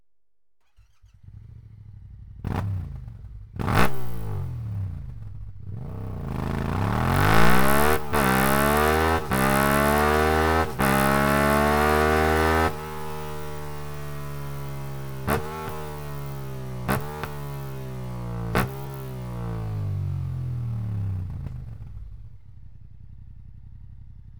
Sound Akrapovic Komplettanlage